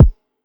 TS - KICK (10).wav